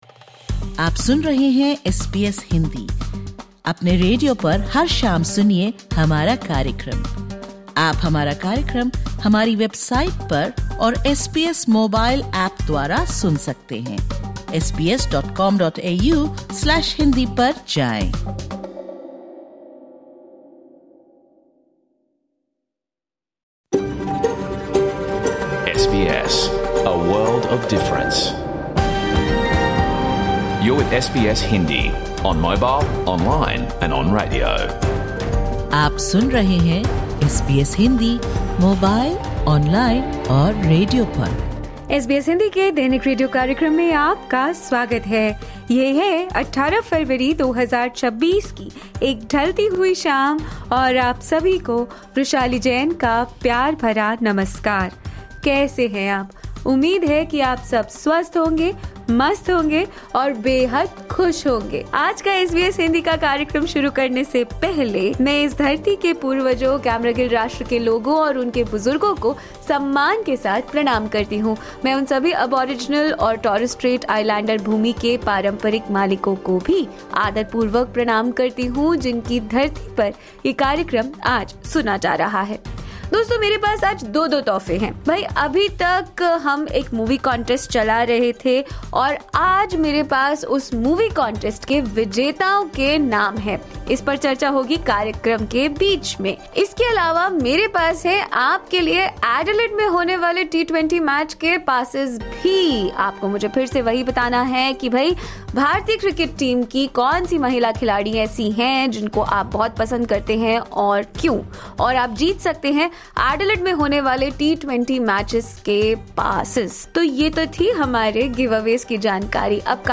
Catch the full radio program of SBS Hindi